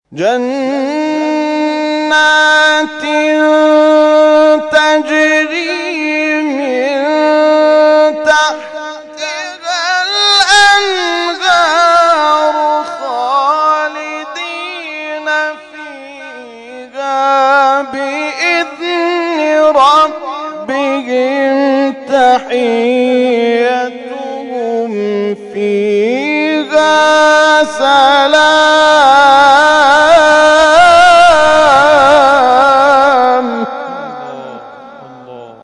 محفل انس با قرآن کریم